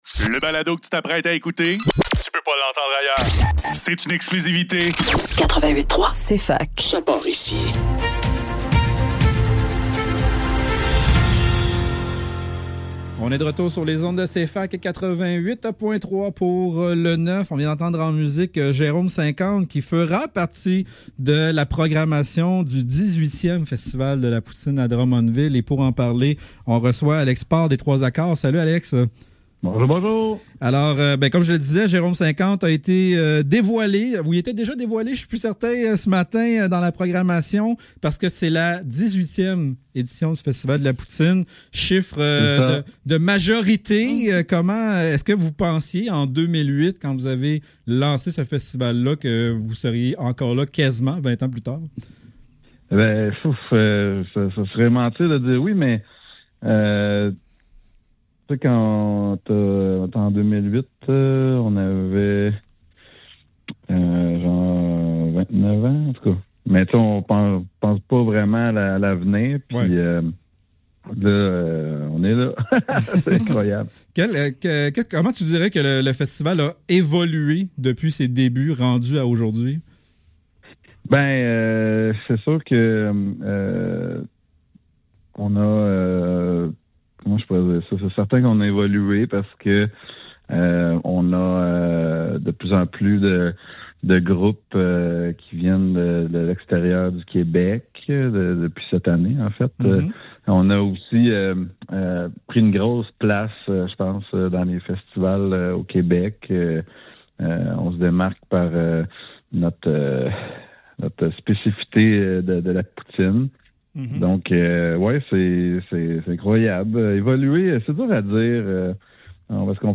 Le Neuf - Entrevue